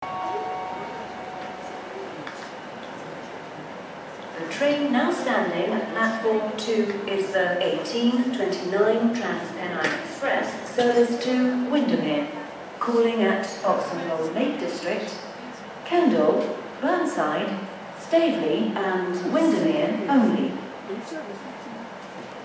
Achtung, Durchsage!